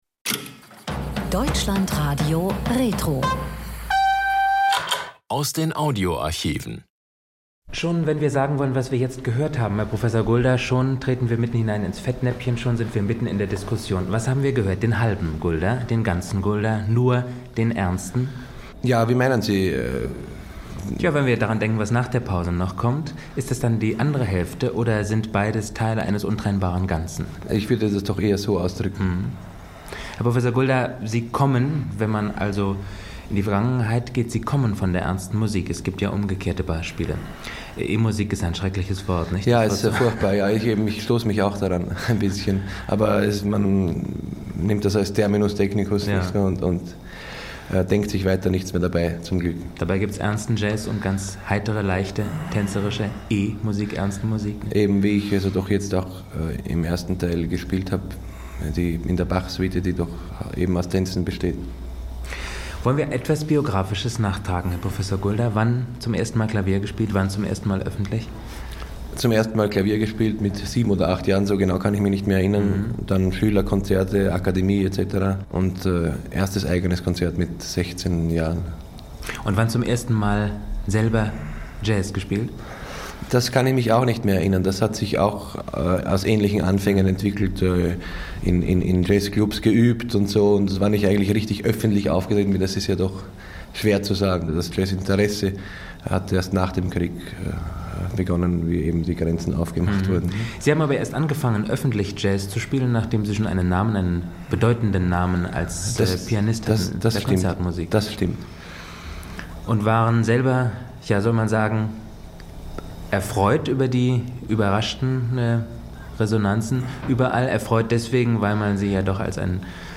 Interview mit Konzert-Pianist und Jazz-Musiker Friedrich Gulda